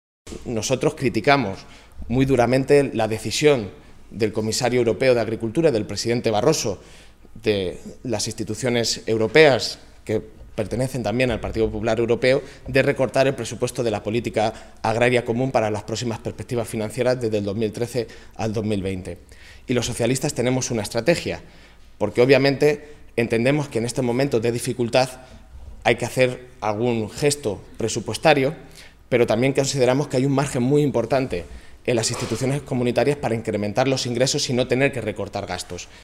Sergio Gutiérrez, eurodiputado del PSOE por Castilla-La Mancha.
Cortes de audio de la rueda de prensa